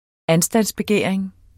Udtale [ ˈansdans- ]